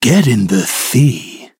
clancy_kill_vo_06.ogg